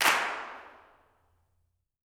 CLAPS 08.wav